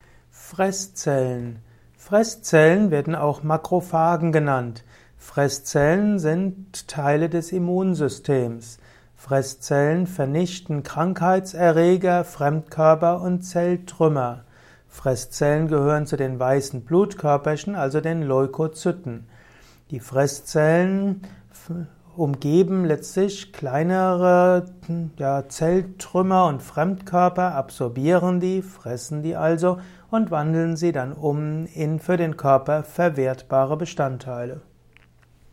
Ein Kurzvortrag über Fresszellen